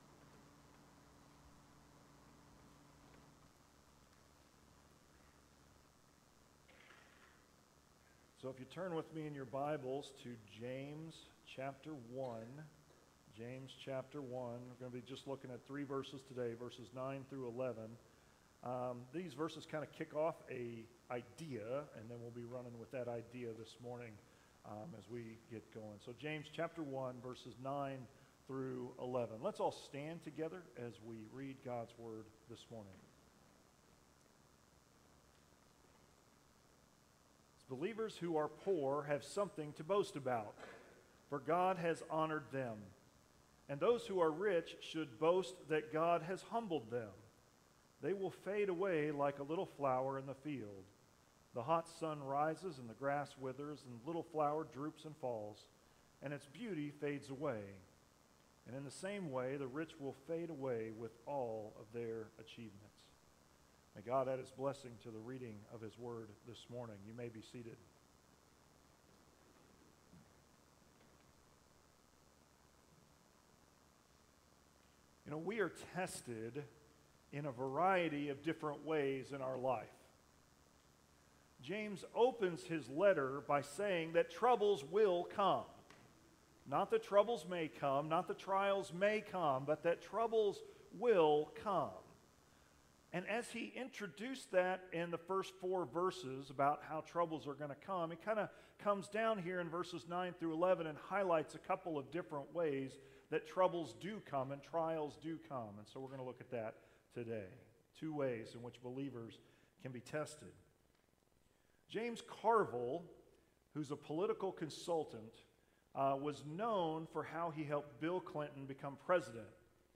Sermons | Centennial Baptist Church